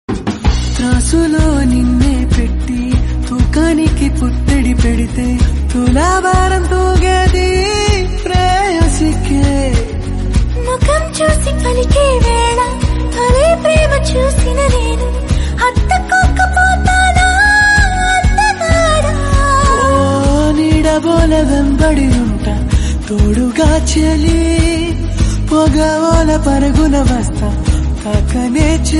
Telugu Ringtonelove ringtonemelody ringtoneromantic ringtone